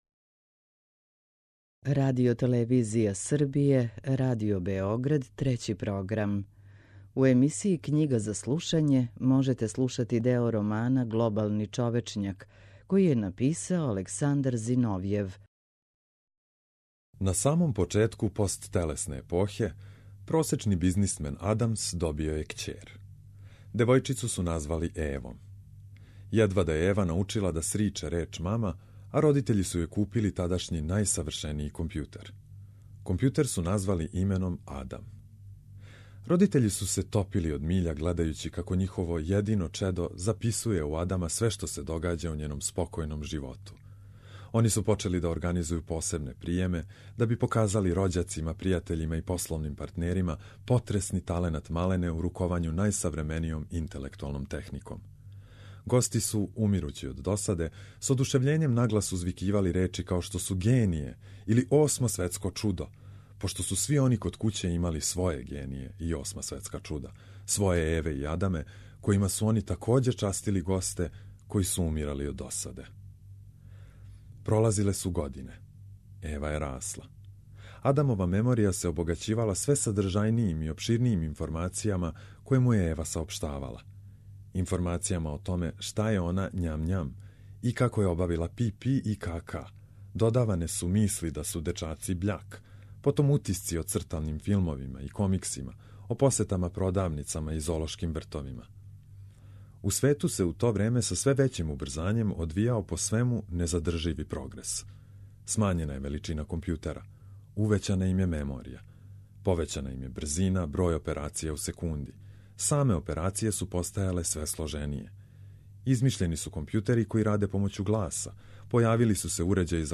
У емисији Књига за слушање можете пратити делове романа Александра Зиновјева „Глобални човечњак”.